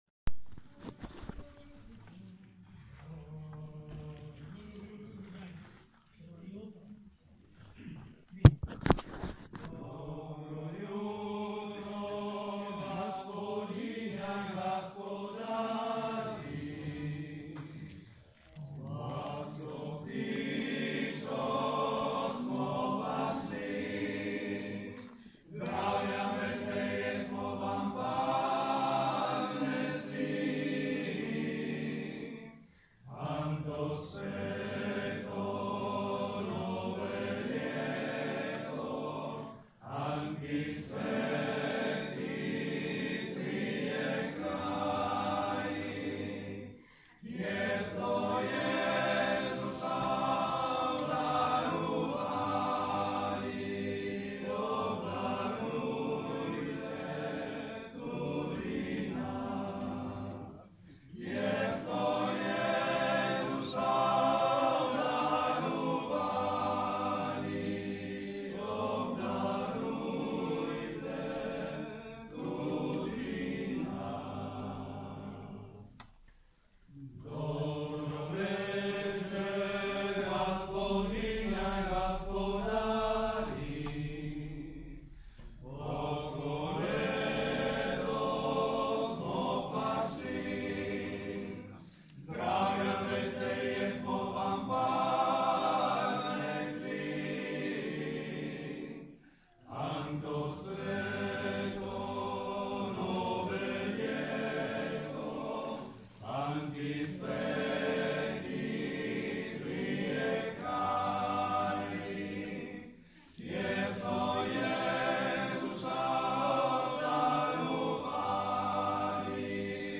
Oggi, nelle Valli del Natisone, si svolge solo a Cicigolis (Pulfero), dove l’ultimo giorno dell’anno la mattina la koleda la fanno i bambini che ricevono noci, noccioline, castagne e caramelle in cambio del canto beneaugurante, mentre la sera gli uomini del paese portano di casa in casa la stella dei Magi.
koleda-scigla-coro-Nediski-puobi.mp3